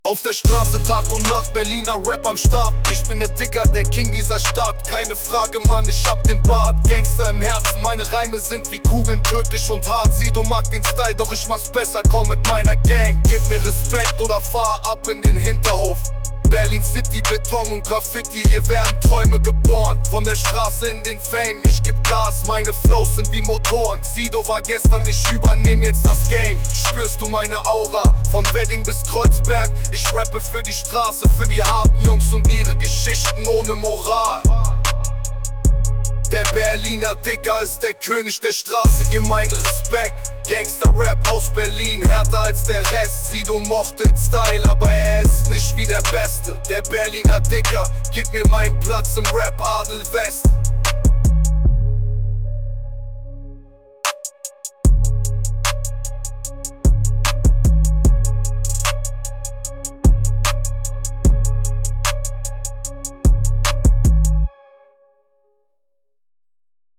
AI Deutsch Rap
The greatest Hip Hop hits generated by AI.